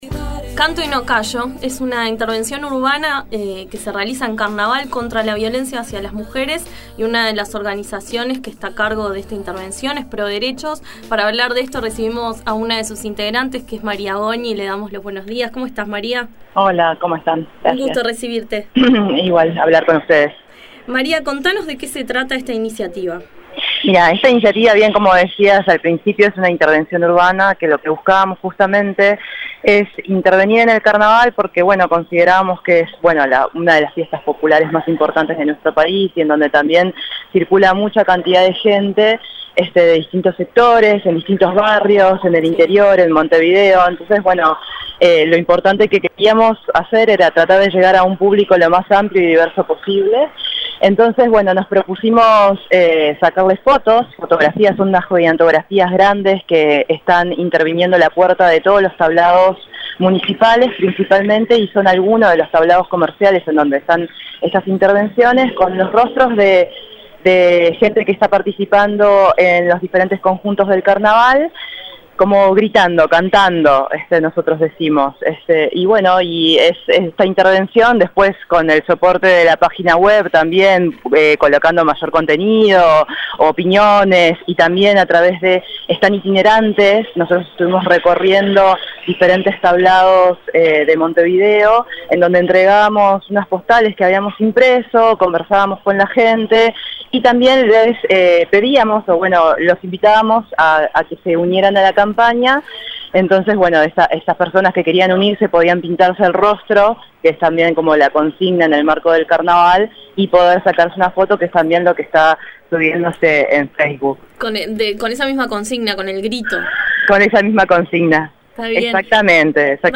Para saber un poco más, La Mañana dialogó con